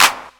Clap 13.wav